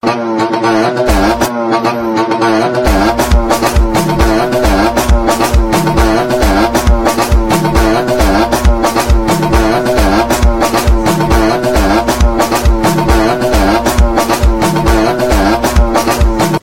African Moto Song Meme Sound Effect
African-Moto-Song-Meme-Sound-Effect-.mp3